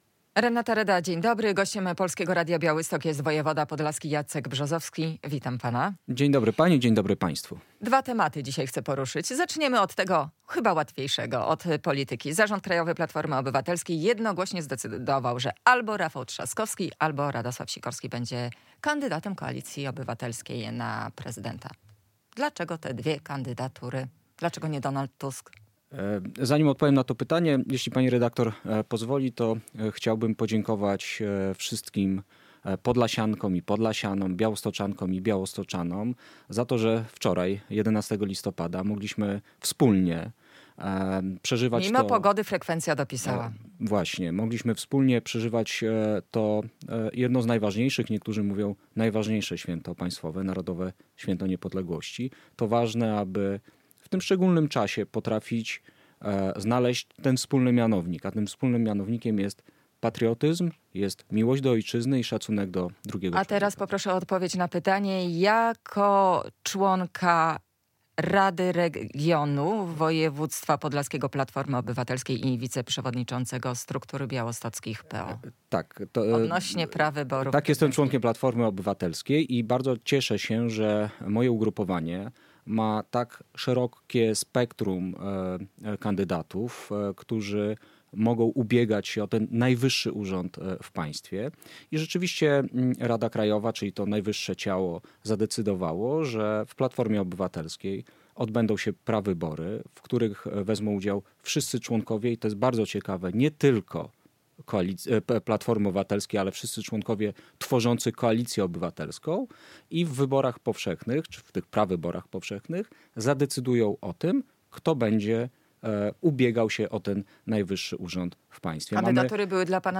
Radio Białystok | Gość | Jacek Brzozowski - wojewoda podlaski